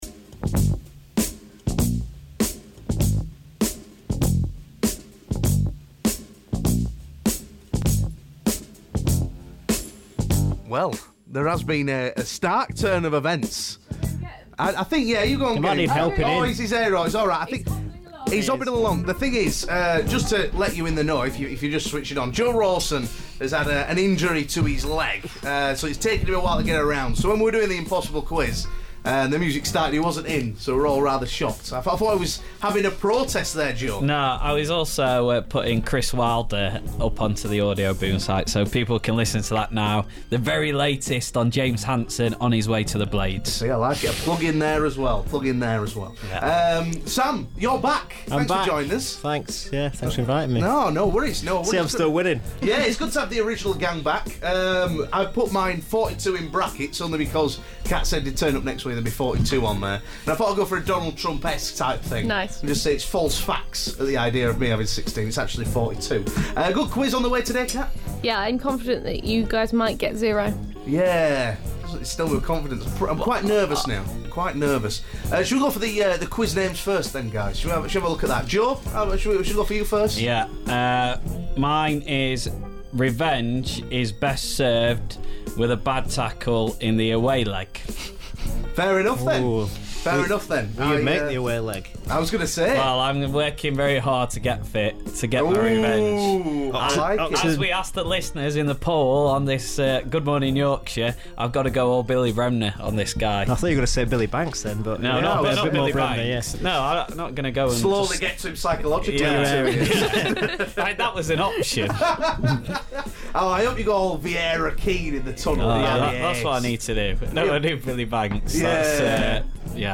Daytimes impossible pub quiz is back!